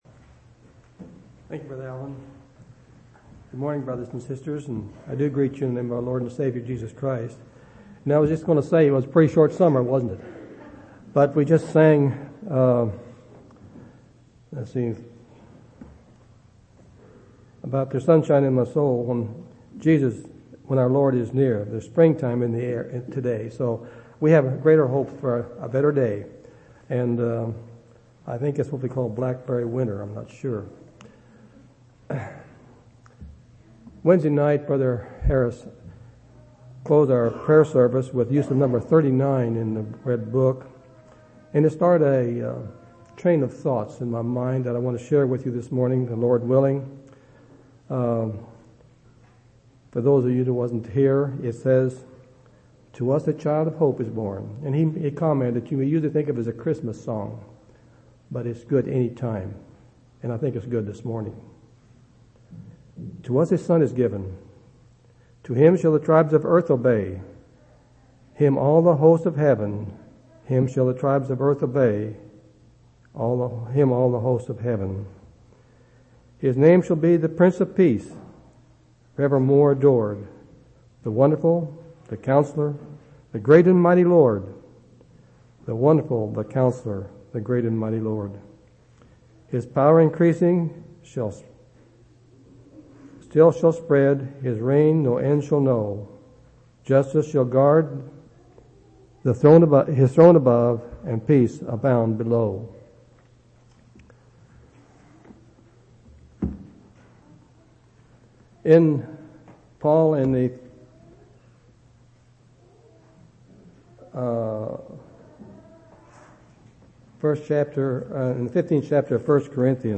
4/24/2005 Location: Temple Lot Local Event